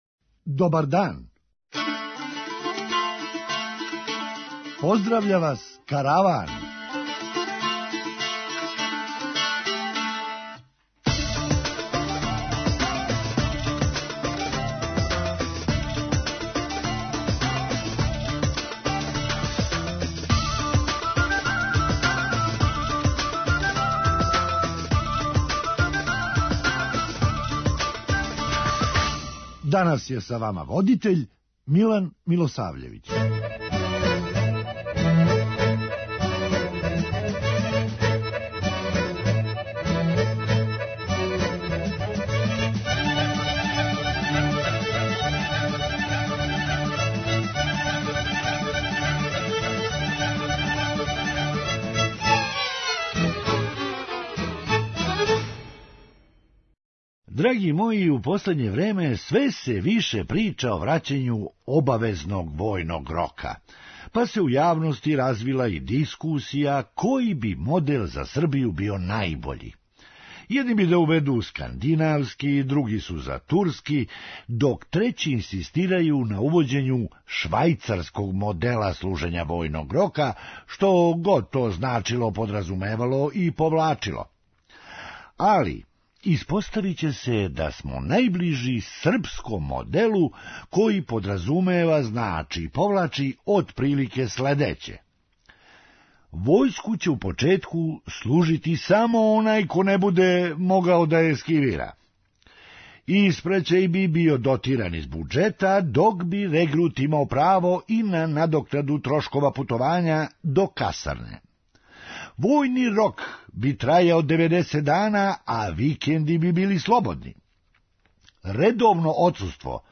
Хумористичка емисија
Али смо много боље пласирани када је реч о извозу памети. преузми : 8.92 MB Караван Autor: Забавна редакција Радио Бeограда 1 Караван се креће ка својој дестинацији већ више од 50 година, увек добро натоварен актуелним хумором и изворним народним песмама.